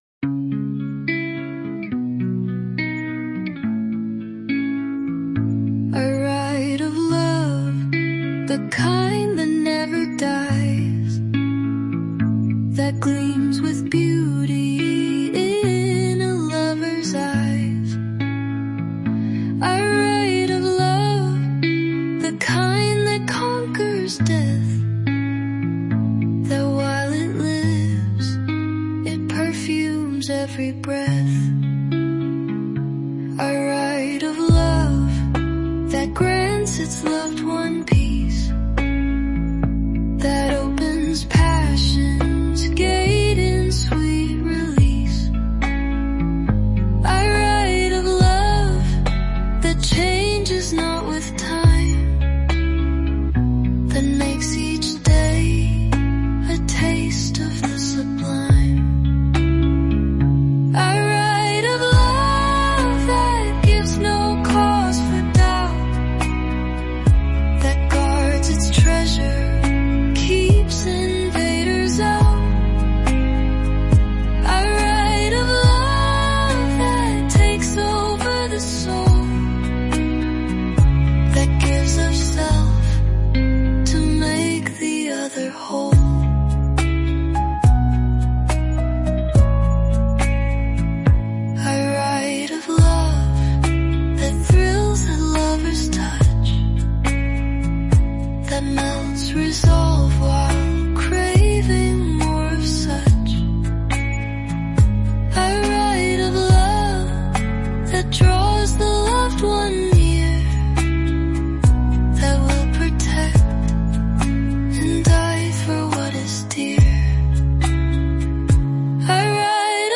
This is really beautiful - the lyrics, the voice, the music.
A stunning musical piece!
The melody perfectly carries the lyrics.
Beautiful, music and all, with calming romantic lyrics!